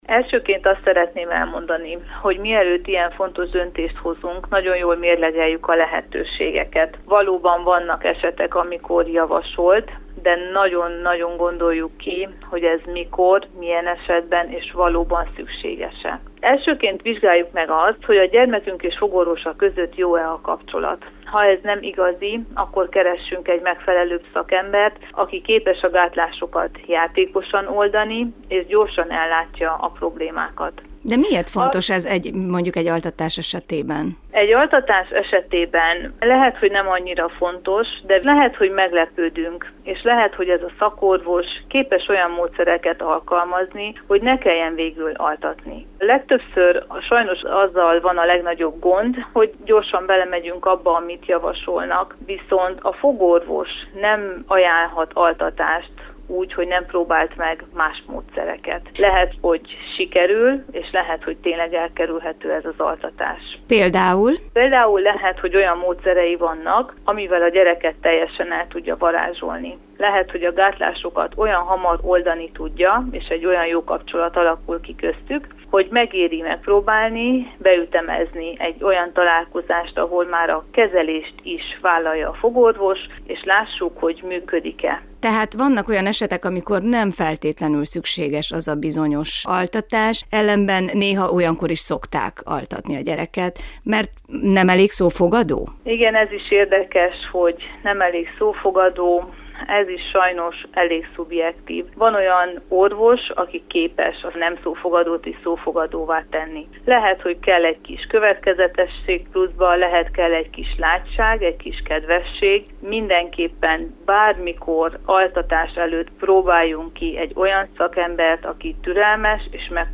Fogorvossal beszélgettünk.